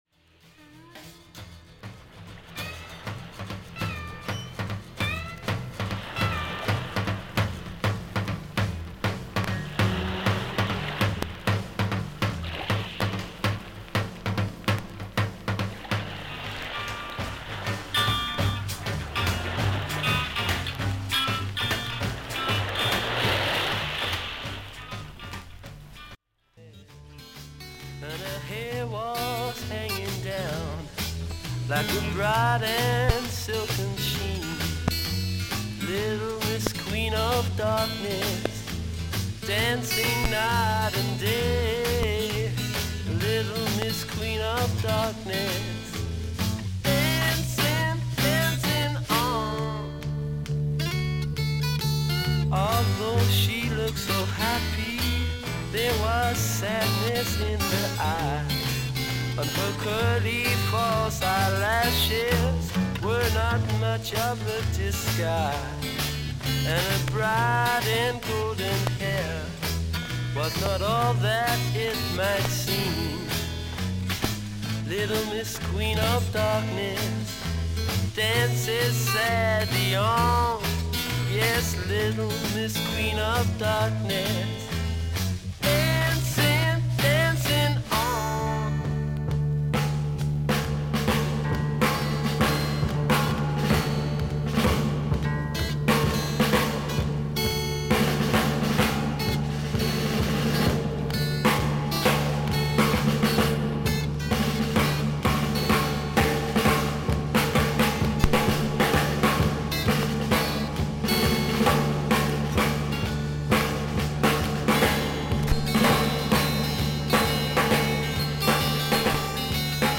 大きなパチノイズあり。環境によっては針飛びの可能性が高いです。